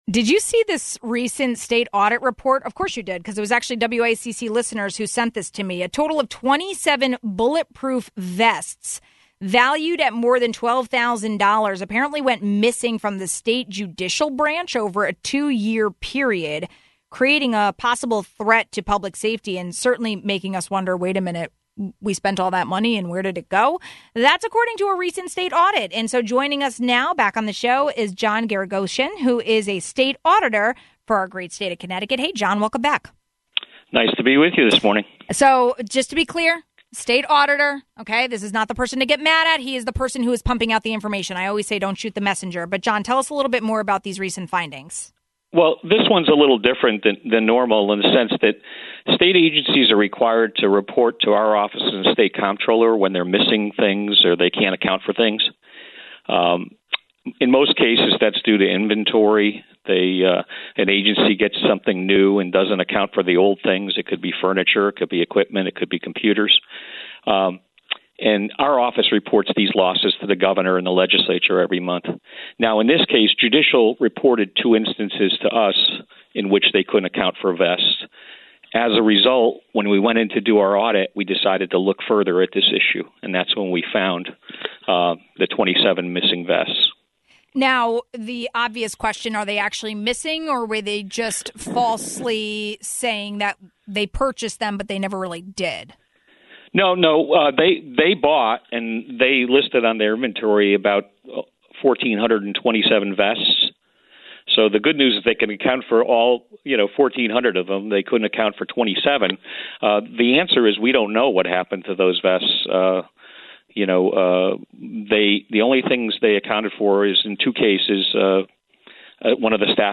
We spoke with John Geragosian, state auditor for Connecticut, to learn more.